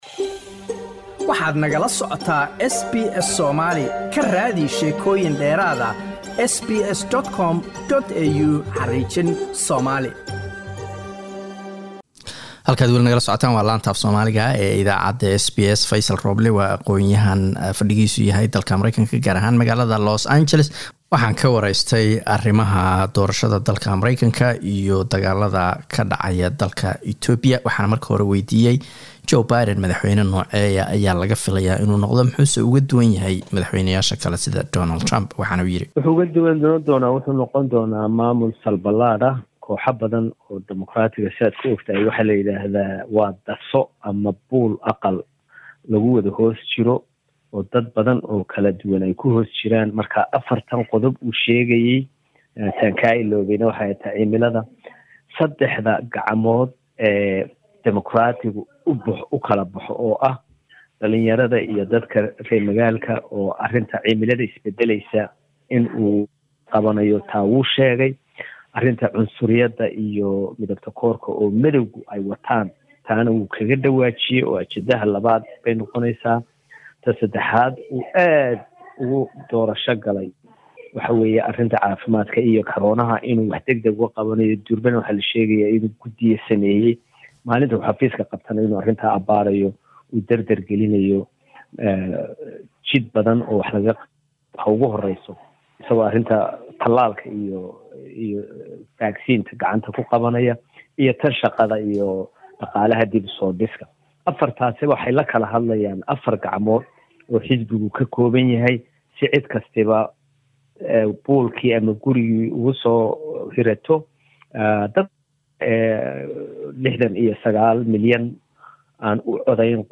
Wuxuu waraysigan ku falan qaynayaa dagaalada Ethiopia iyo doorashada maraykanka.